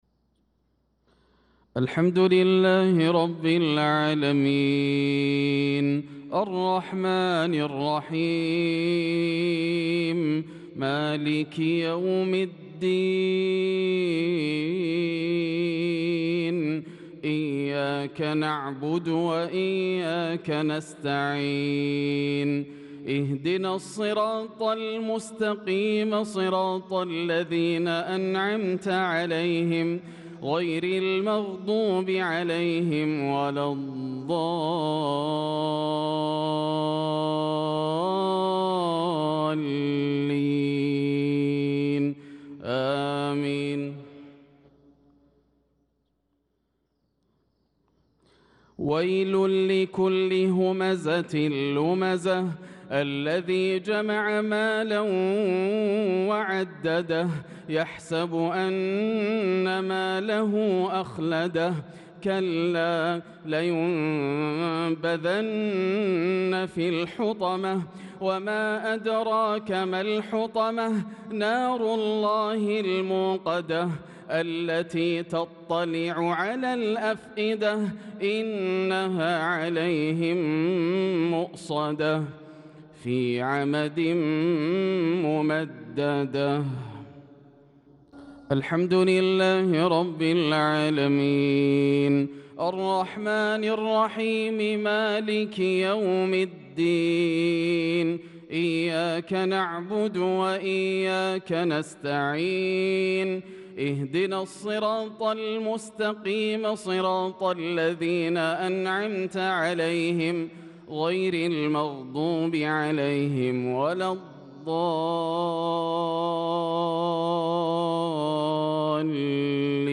صلاة المغرب للقارئ ياسر الدوسري 12 ذو القعدة 1445 هـ
تِلَاوَات الْحَرَمَيْن .